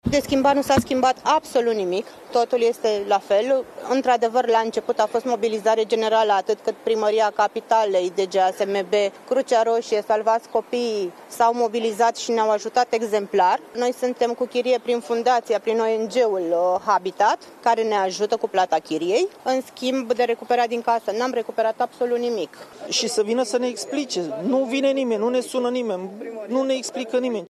„De schimbat, nu s-a schimbat absolut nimic. Totul este la fel. Într-adevăr, la început a fost mobilizare generală. Noi suntem cu chirie prin ONG-ul Habitat care ne ajută cu plata chirie, în schimb, de recuperat din casă nu am recuperat nimic”, a explicat o femeie.
„Și să vină să ne explice. Nu vine nimeni, nu ne sună nimeni”, a spus un alt bărbat.
17apr-20-Vox-–-Locatari-–-Ne-descurcam-cum-putem.mp3